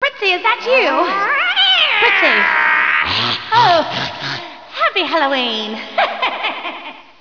witchandcat.wav